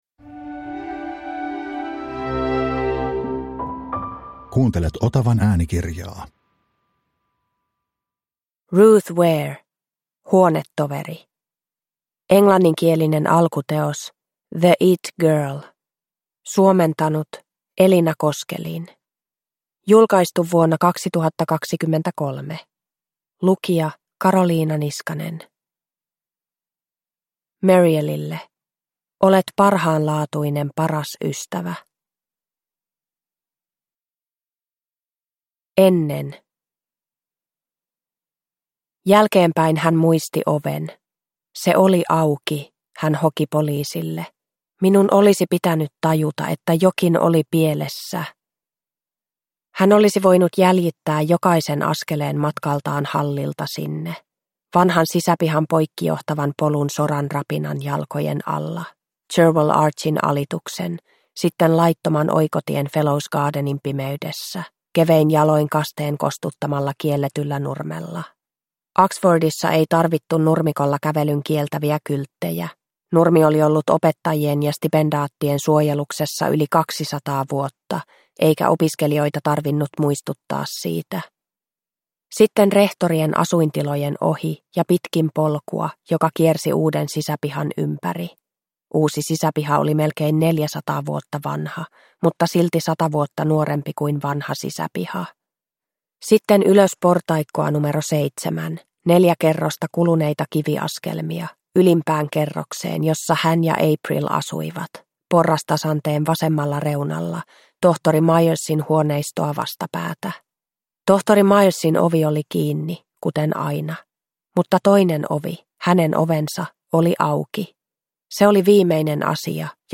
Huonetoveri – Ljudbok – Laddas ner